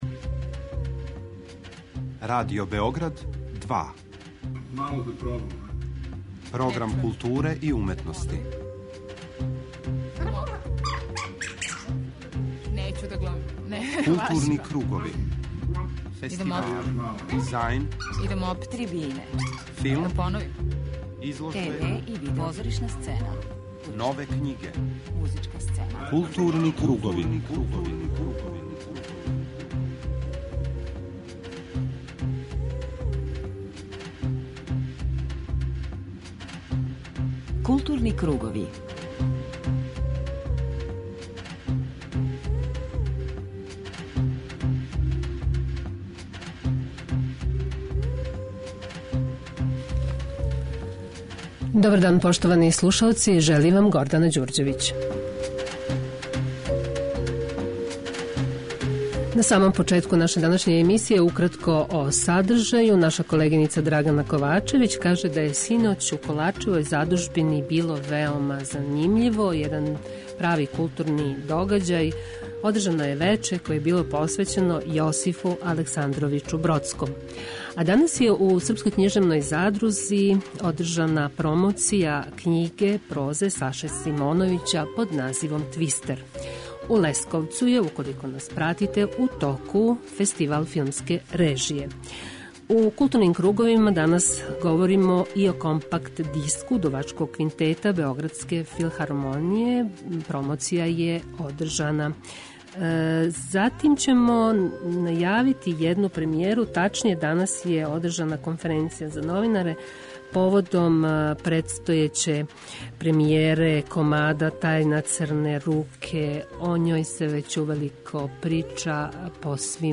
преузми : 41.13 MB Културни кругови Autor: Група аутора Централна културно-уметничка емисија Радио Београда 2.